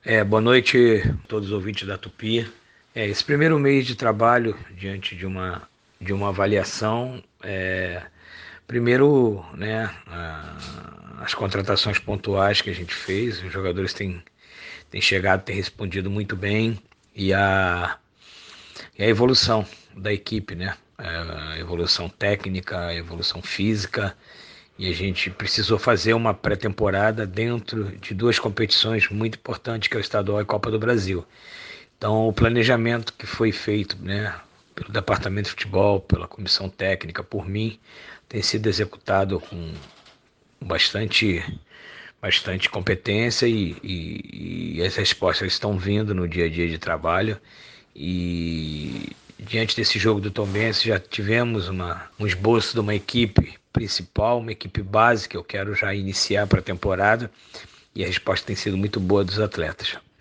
Em entrevista exclusiva à Super Rádio Tupi, treinador comemorou o acerto do planejamento no início da temporada
Em entrevista ao Giro Esportivo